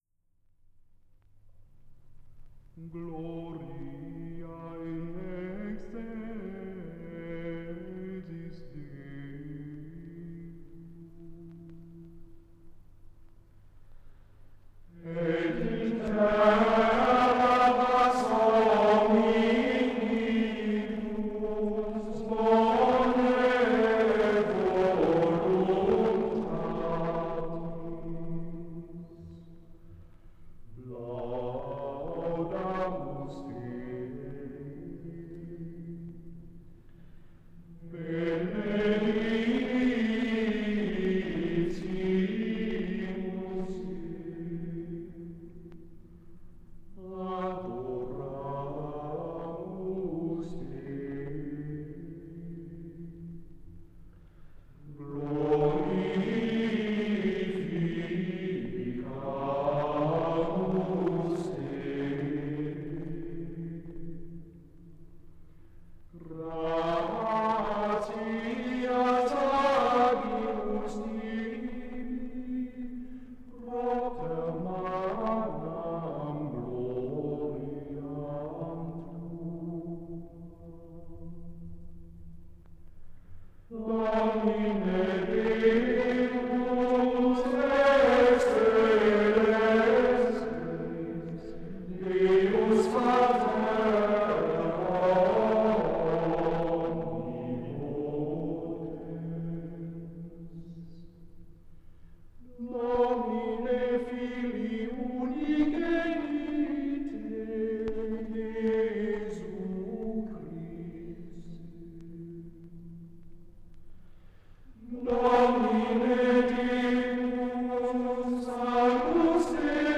Gesang: Schola gregoriana
aufgenommen in der Klosterkirche Knechtsteden